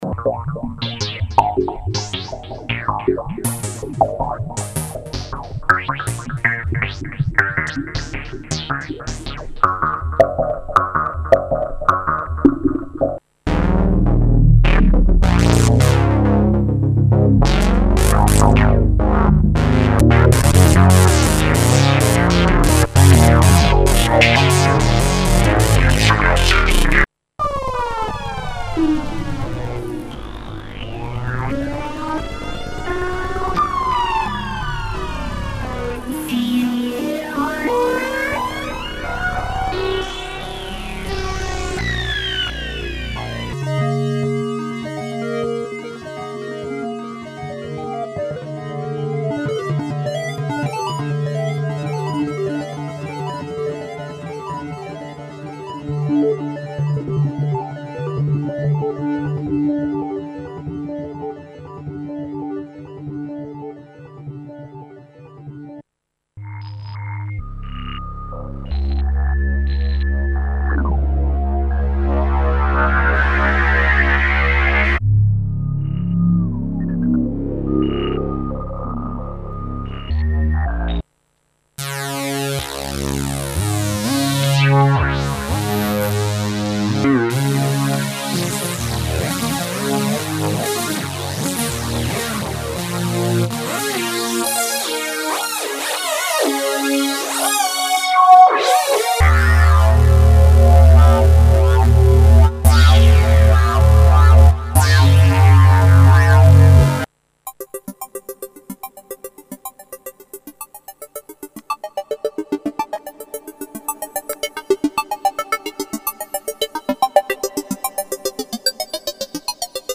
various electronic sounds